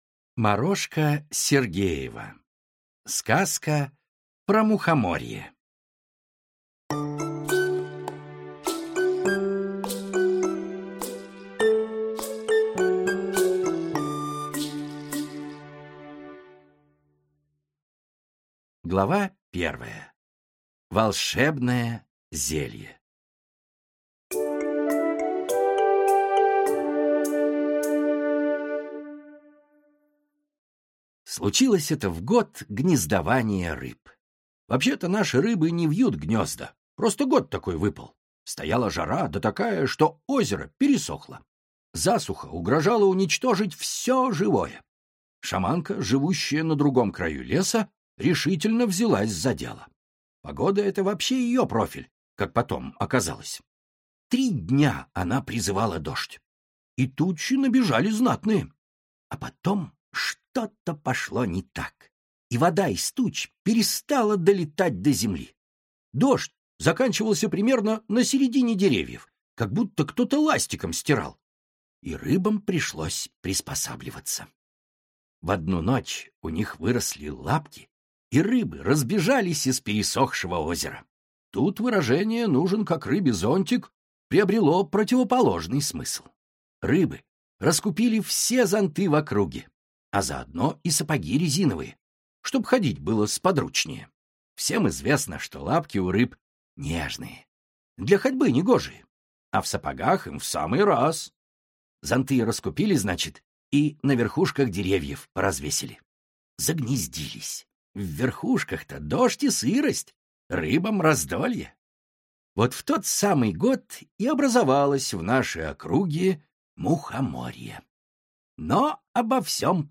Аудиокнига Сказка про Мухоморье | Библиотека аудиокниг